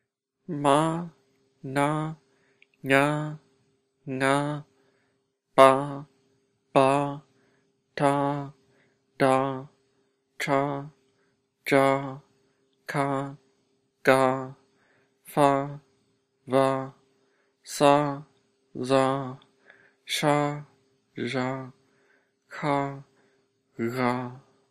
Consonants: